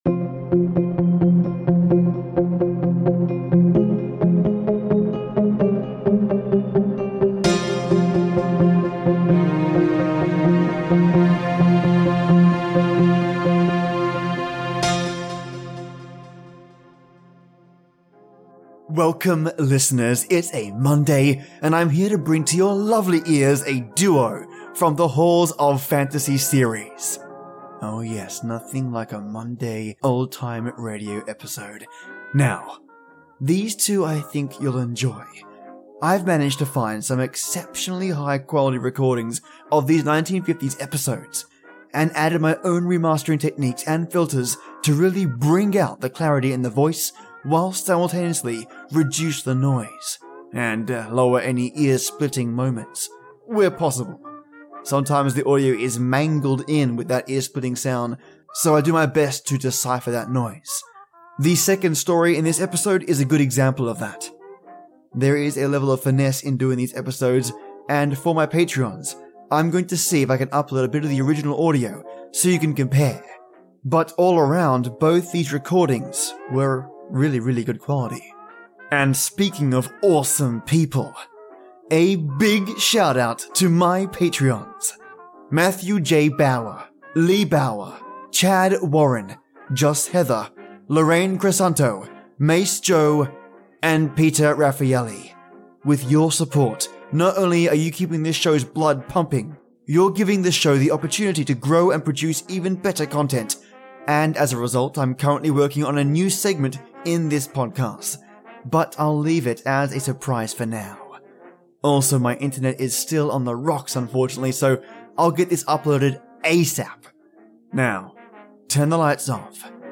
I’ve managed to find some exceptionally high quality recordings of these 1950’s episodes, and added my own remastering techniques and filters to really bring out the clarity in the voice whilst simultaneously reduce the noise, and lower any “ear splitting” moments – where possible.